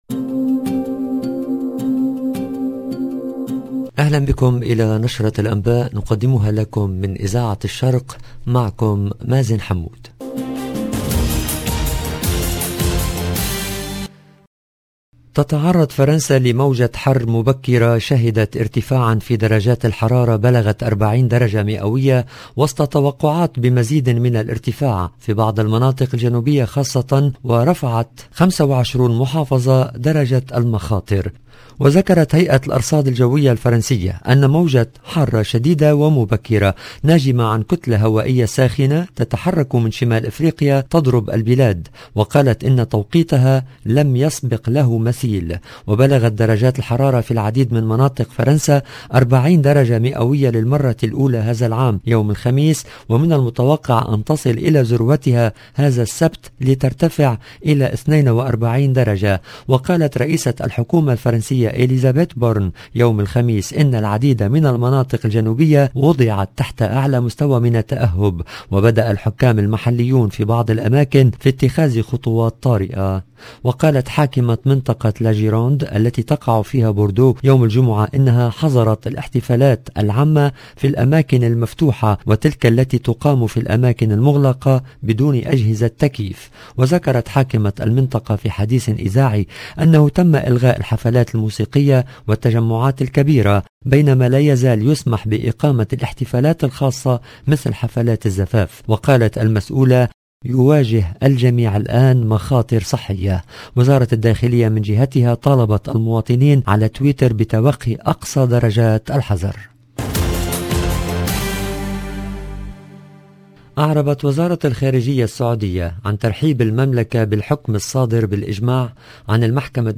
EDITION DU JOURNAL DU SOIR EN LANGUE ARABE DU 17/6/2022